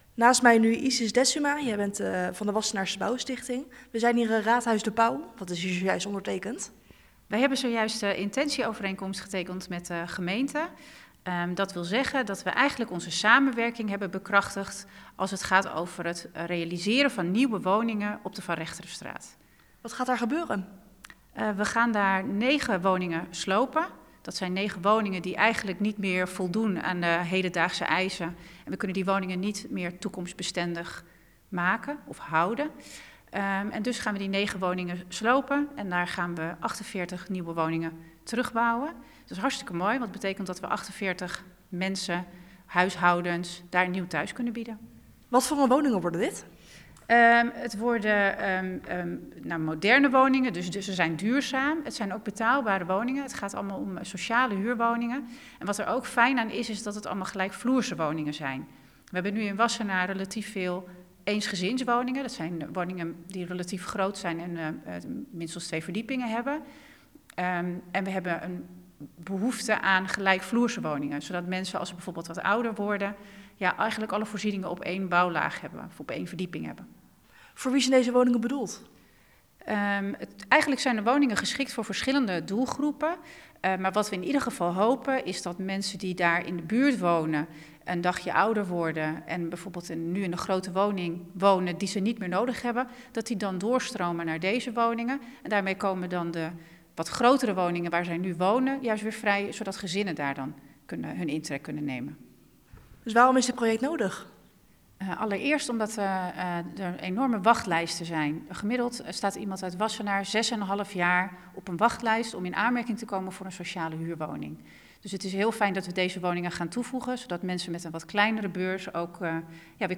Verslaggever
in gesprek